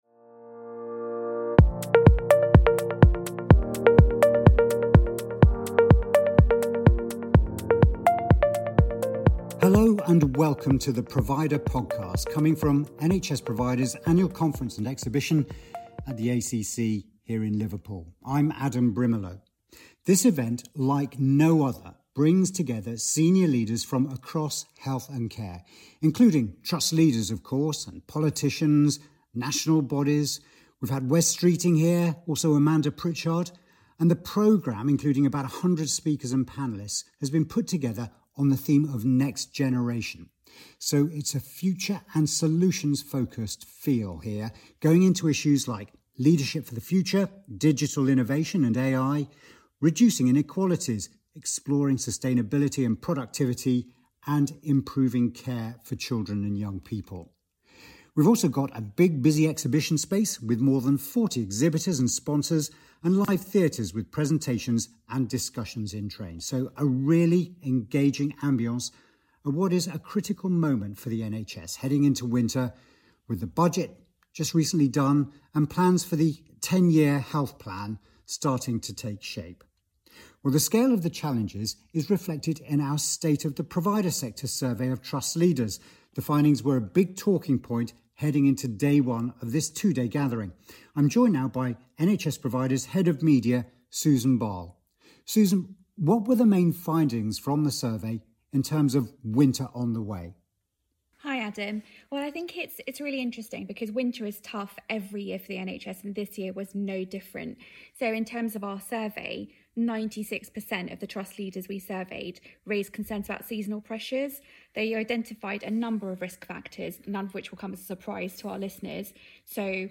This special edition of the provider podcast comes from NHS Providers’ annual conference and exhibition in Liverpool.